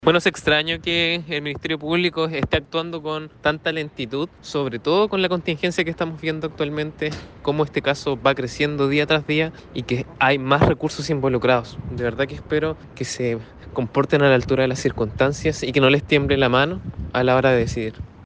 En igual sentido se manifestó el consejero Regional, Piero Blas, quien apuntó que “es extraño que el Ministerio Público esté actuando con tanta lentitud”.